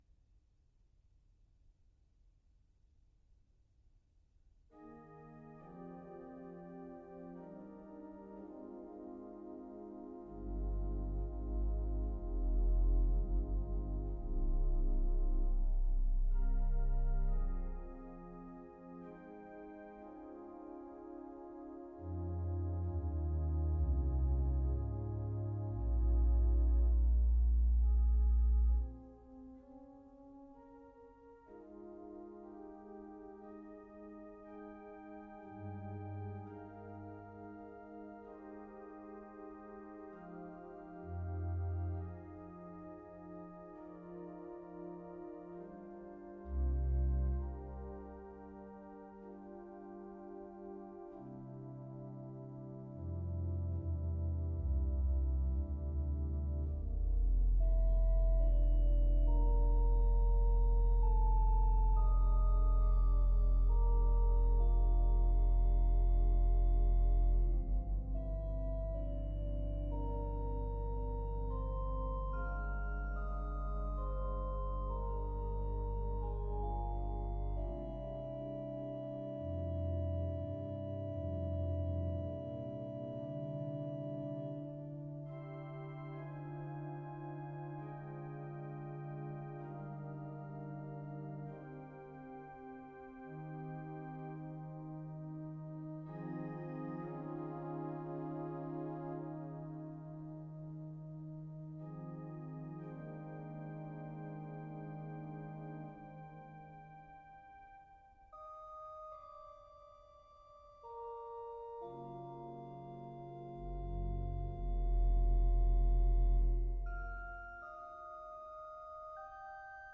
organ Duration